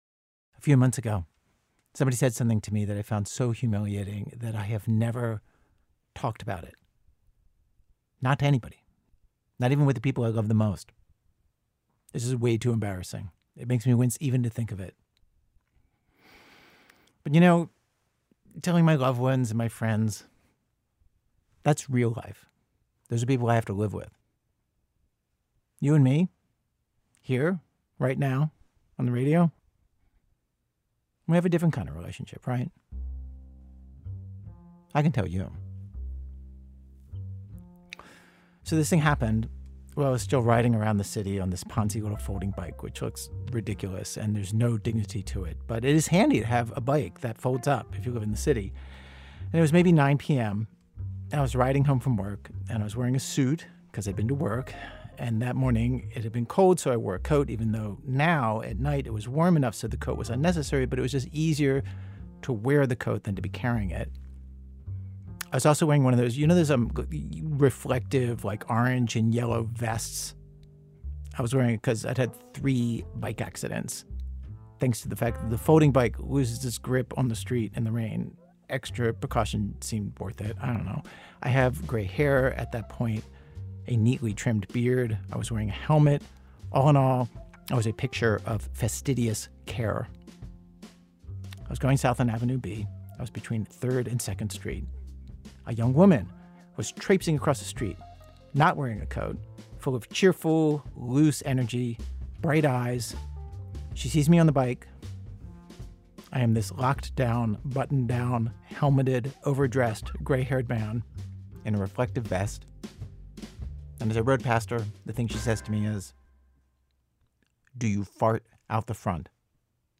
Note: The internet version of this episode contains un-beeped curse words.
Host Ira Glass tells a story he’s never told anyone before, about something someone said to him. (4 minutes)